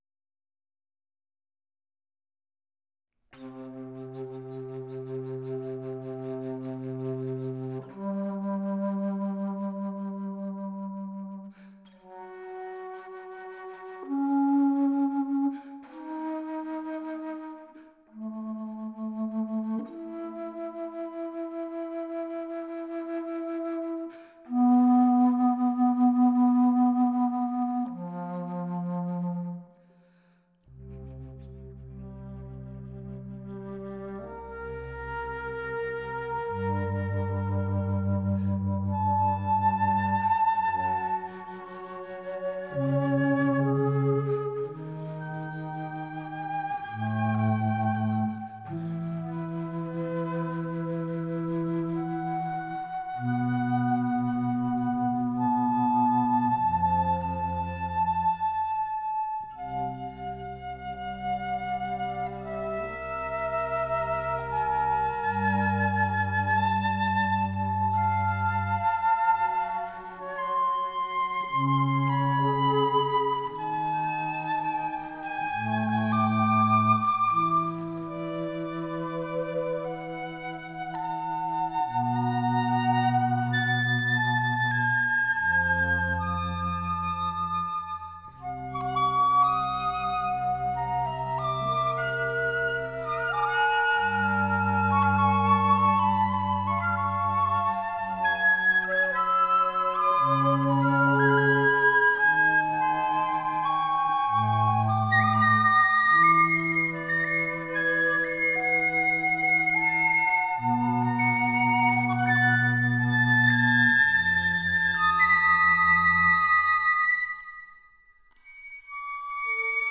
A flute quartet, in just intonation